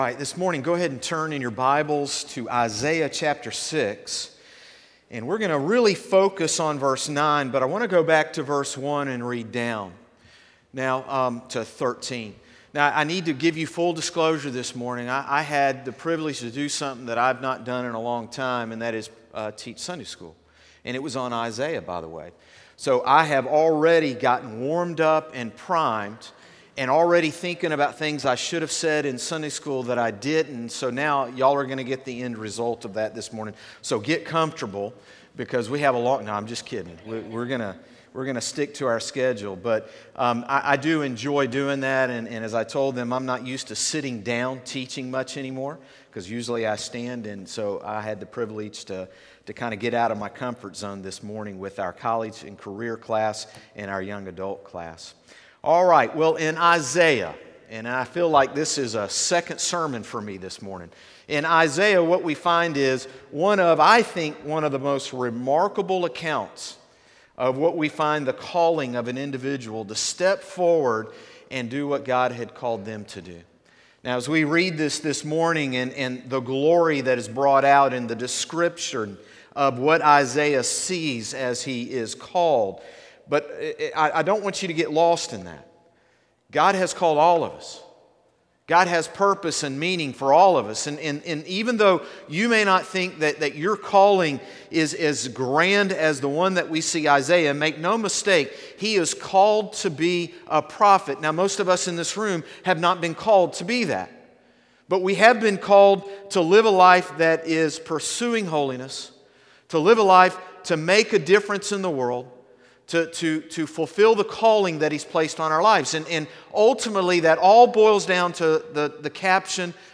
Morning Service - Go and Tell | Concord Baptist Church
Sermons - Concord Baptist Church